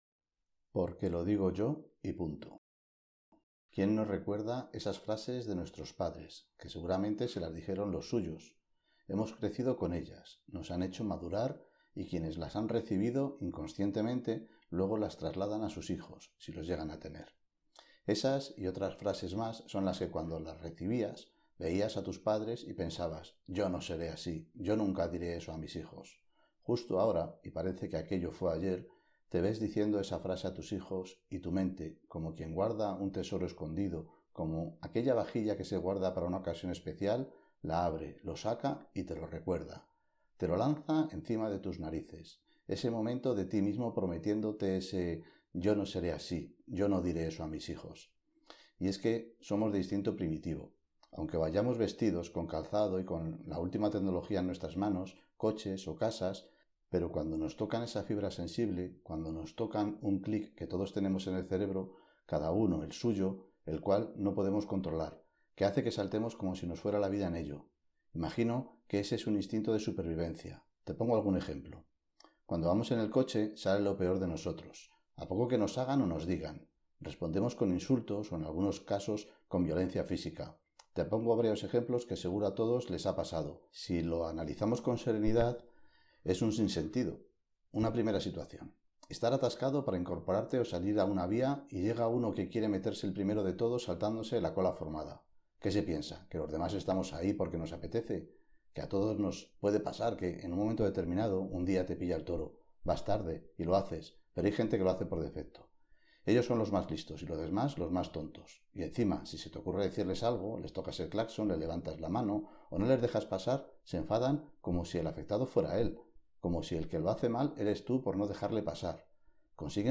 Audiolibro – Todo va a salir bien – PORQUE LO DIGO YO Y PUNTO – – Asociación Esclerosis Múltiple